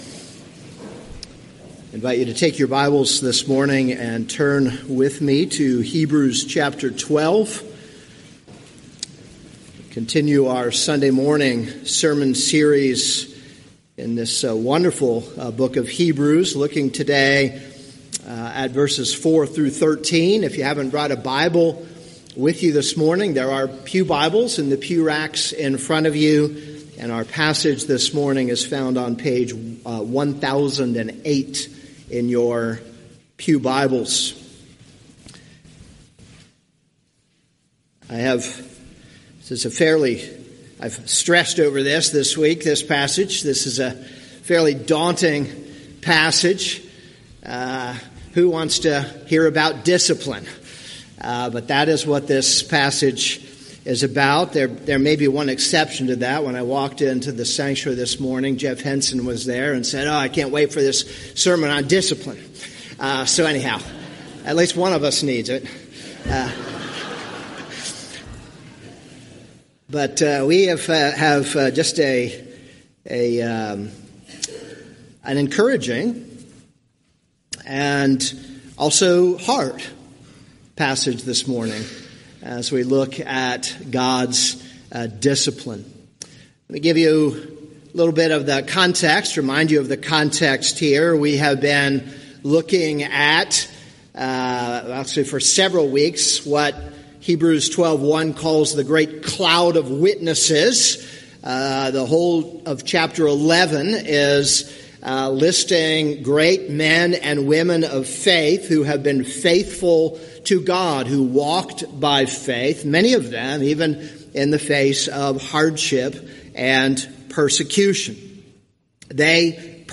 This is a sermon on Hebrews 12:4-13.